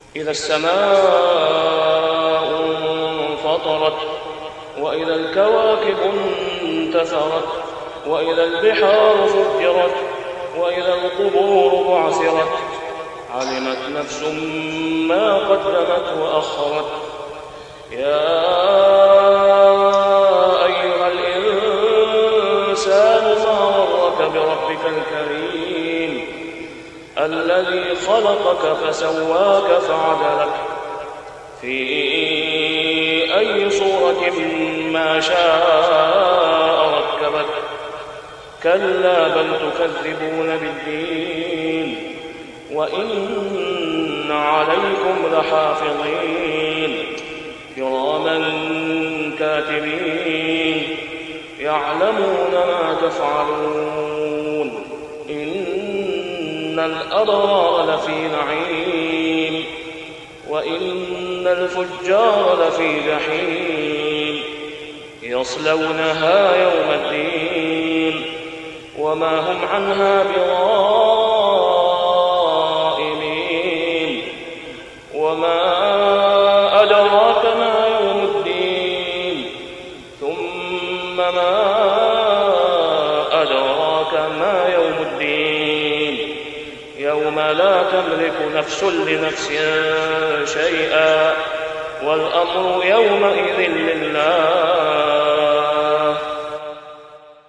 سورة الإنفطار > السور المكتملة للشيخ أسامة خياط من الحرم المكي 🕋 > السور المكتملة 🕋 > المزيد - تلاوات الحرمين